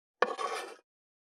579魚切る,肉切りナイフ,
効果音厨房/台所/レストラン/kitchen食器食材
効果音